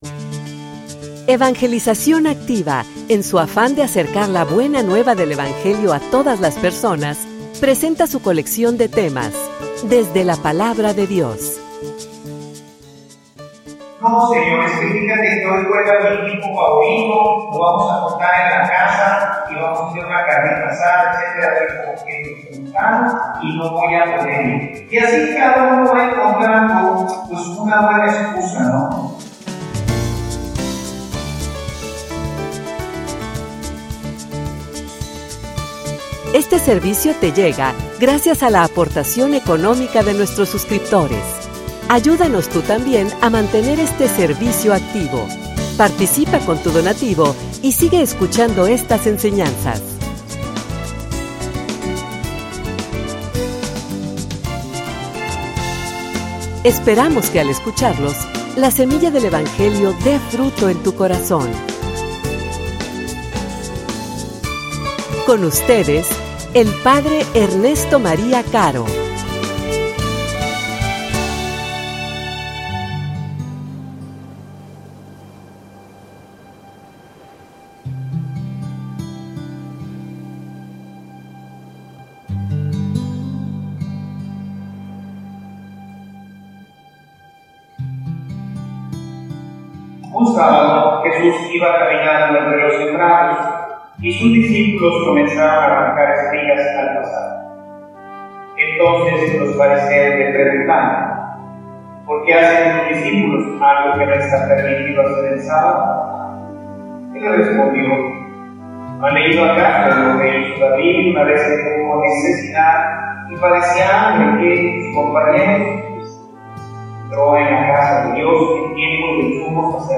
homilia_El_domingo_es_para_el_hombre.mp3